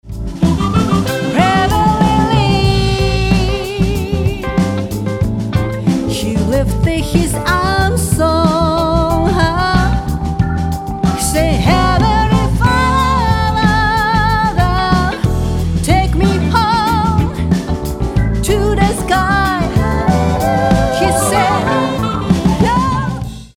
70年代ソウルミュージックの名曲をカヴァーしたリスペクトアルバム